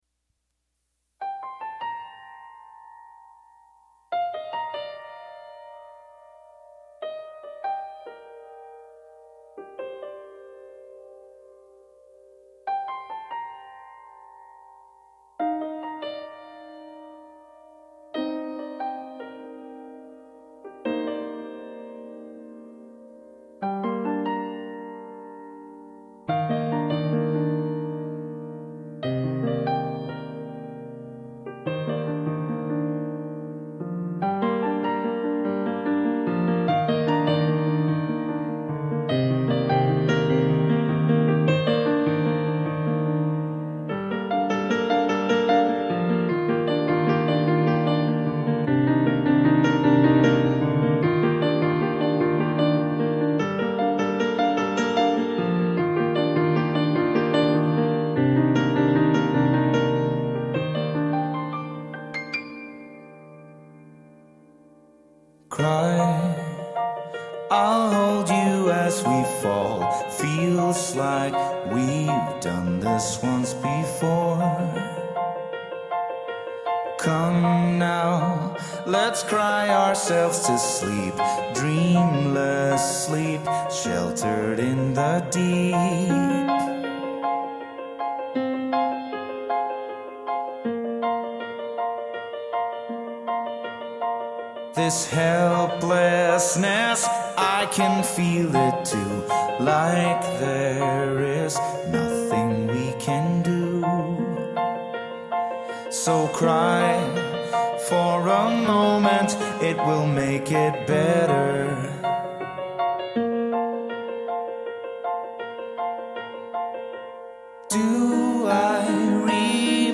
Swedish prog band
bonus track piano version